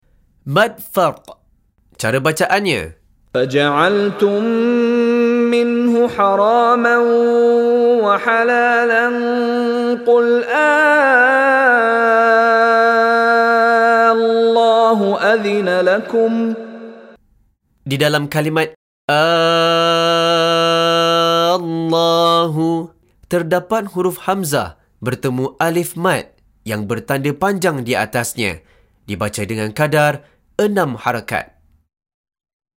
Contoh Bacaan dari Sheikh Mishary Rashid Al-Afasy
Dipanjangkan sebutan huruf Mad dengan kadar 6 harakat.